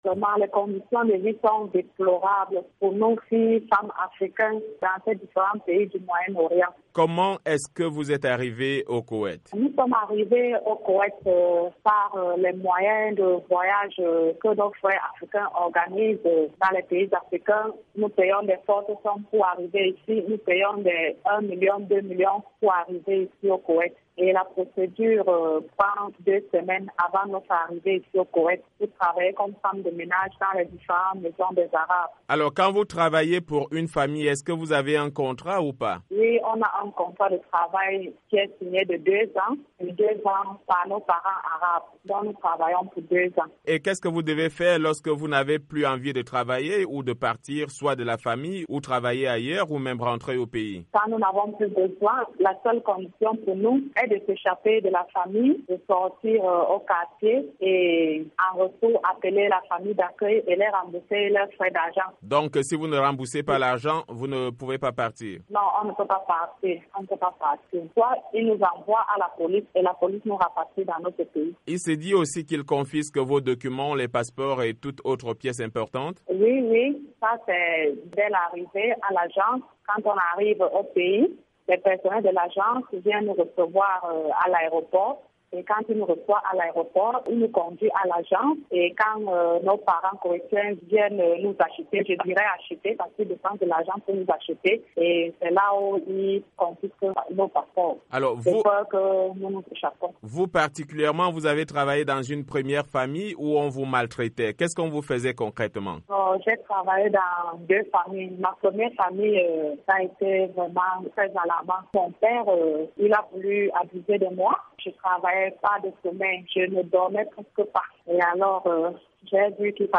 VOA Afrique a interviewé une africaine qui vit depuis quelque temps au Koweït, en quête d’une vie meilleure. Elle dénonce un esclavagisme qui ne dit pas son nom, dans lequel vivent des Africaines dans ce pays.